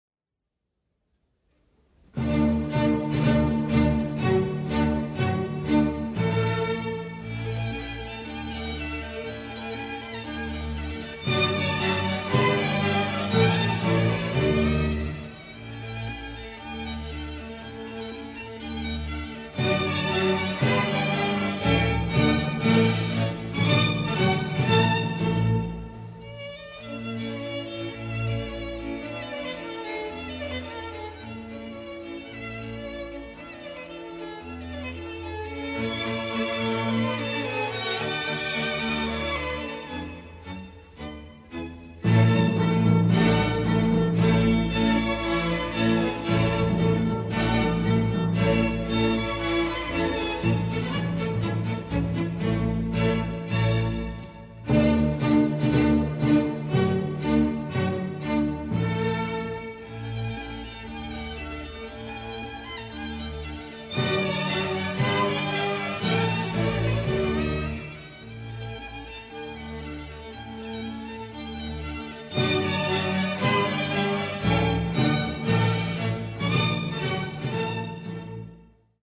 Como muestra de su inagotable creatividad y genio, Mozart compuso en 1776 la Serenata para orquesta, No. 6 en Re mayor, K. 239, conocida como la Serenata Nocturna.